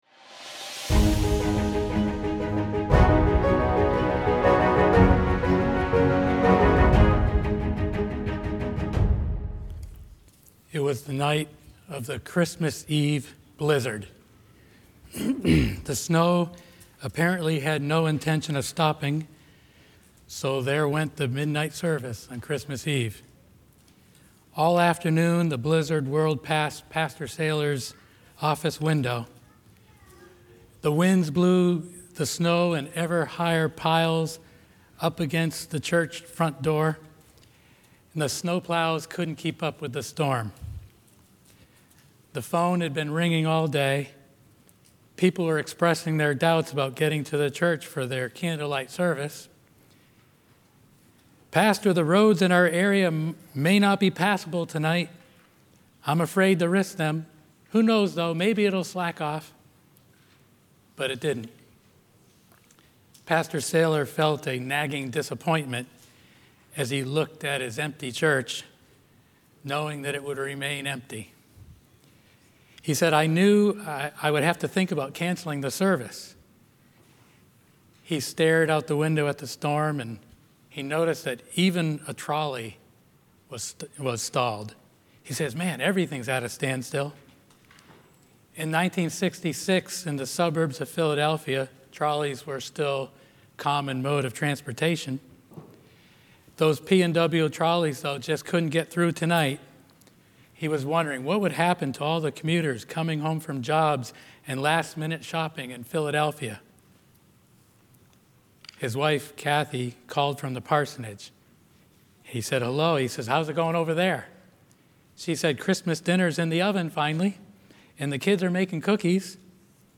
A message from the series "Central Sermons."